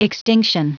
Prononciation du mot extinction en anglais (fichier audio)
Prononciation du mot : extinction